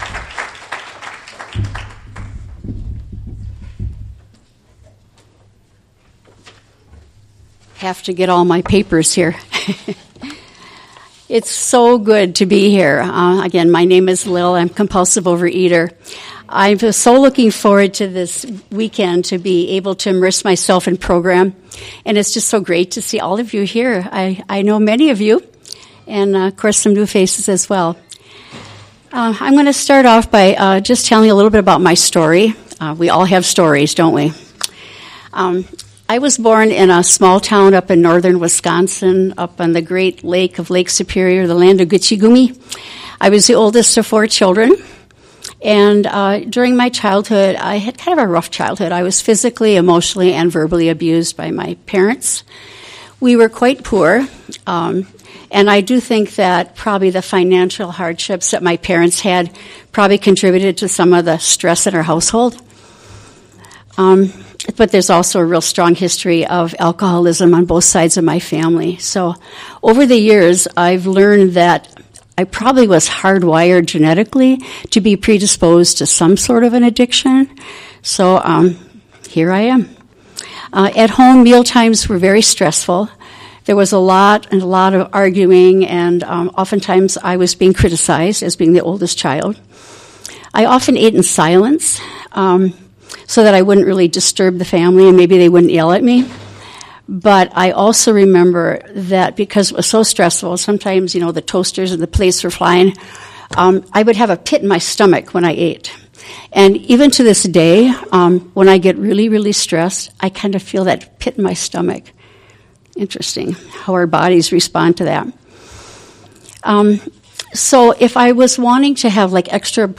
2024 OA Milwaukee Area Convention / “Extending Hand and Heart”…